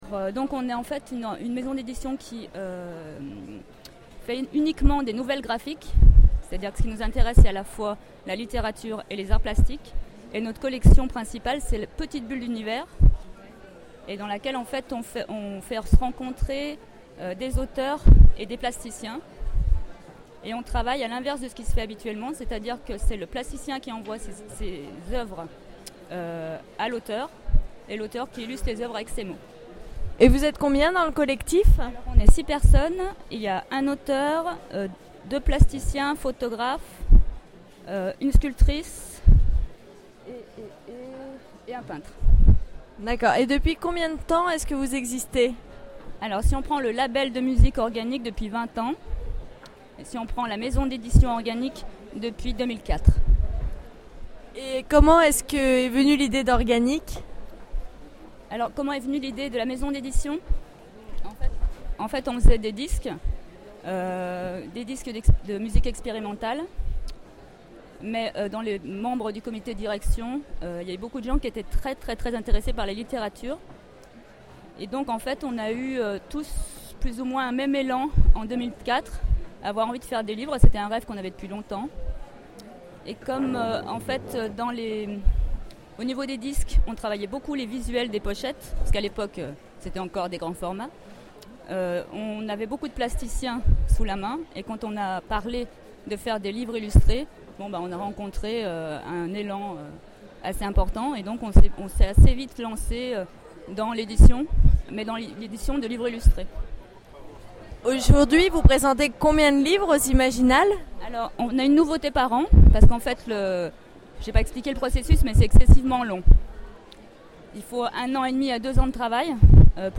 Imaginales 2011 : rencontre avec Organic
Mots-clés Rencontre avec une maison d'édition Conférence Partager cet article